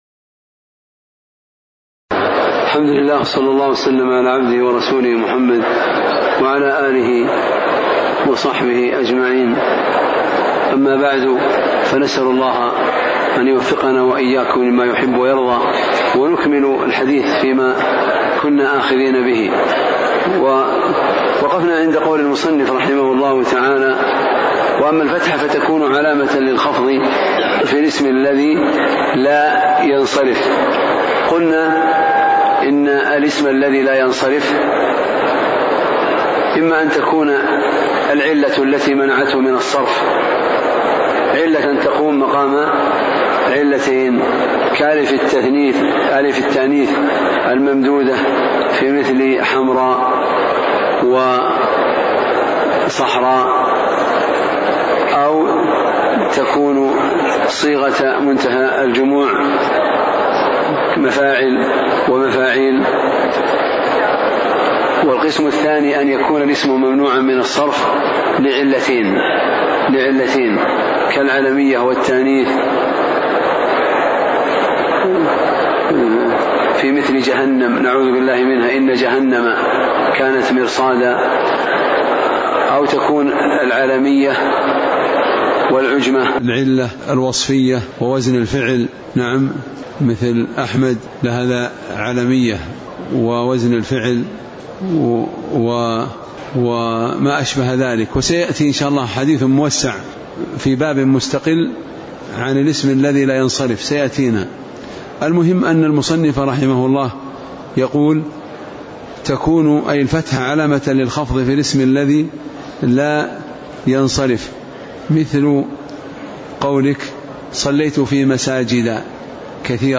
تاريخ النشر ٤ محرم ١٤٤٠ هـ المكان: المسجد النبوي الشيخ